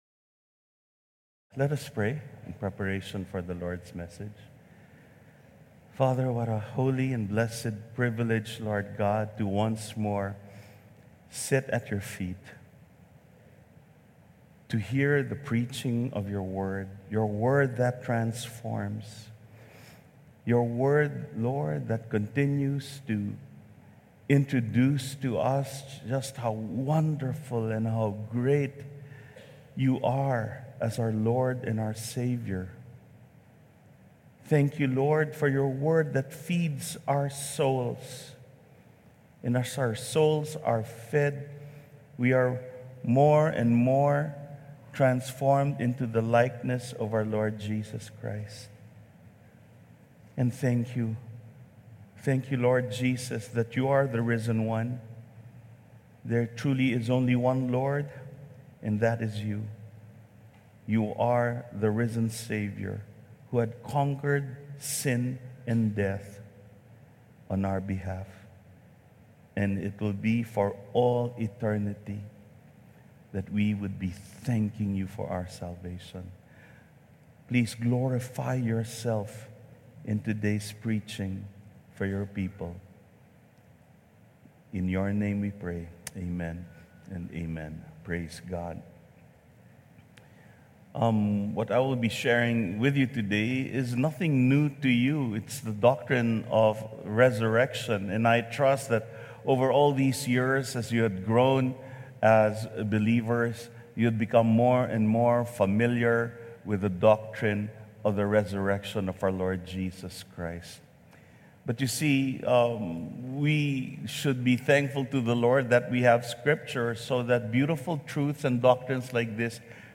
WATCH AND BE BLESSED 2026 Resurrection Sunday Message 1 Videos April 5, 2026 | 9 A.M Service 2026 Resurrection Sunday Message: Raised Up With Christ | Ephesians 2:5-7 Information Information Download the Sermon Slides here.